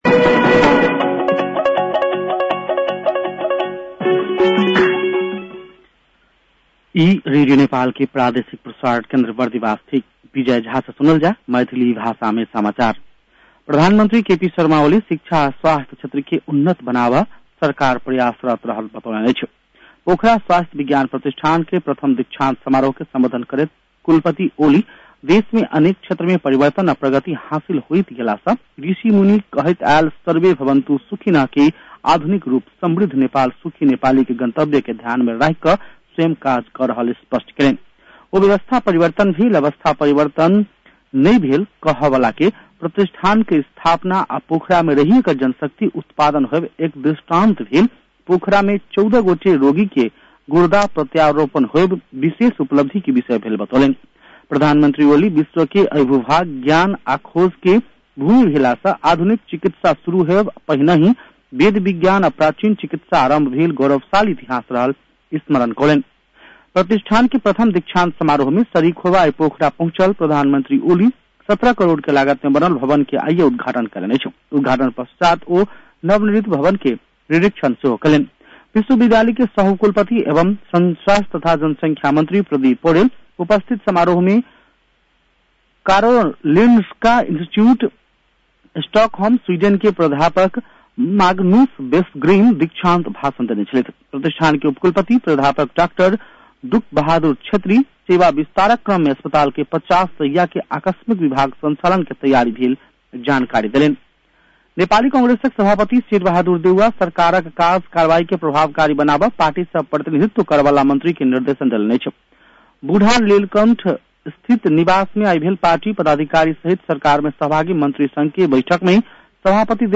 मैथिली भाषामा समाचार : ४ माघ , २०८१